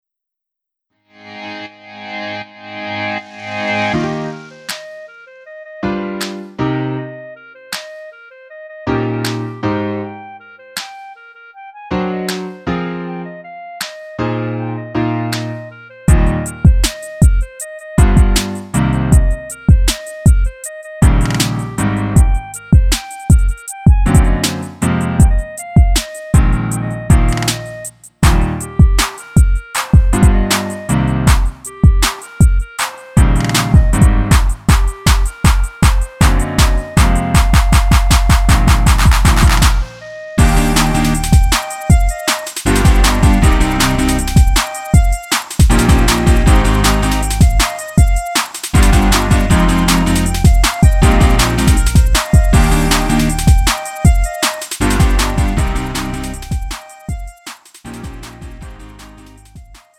음정 -1키 3:00
장르 가요 구분